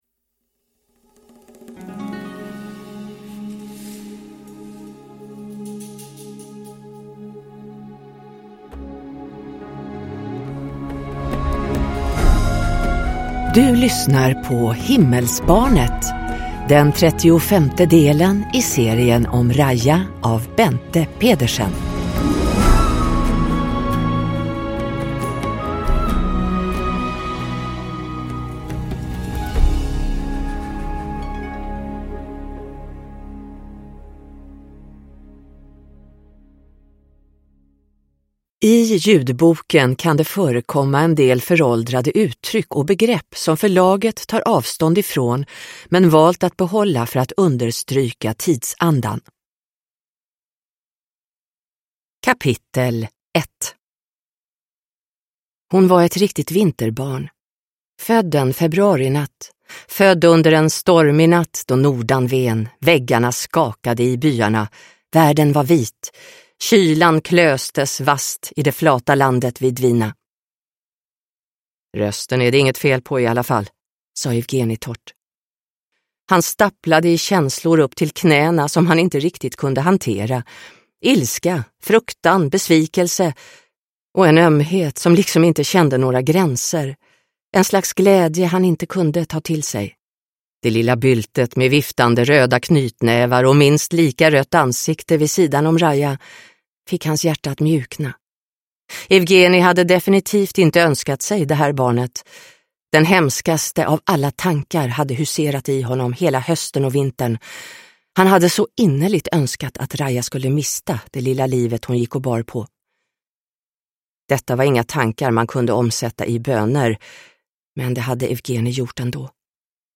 Himmelsbarnet – Ljudbok – Laddas ner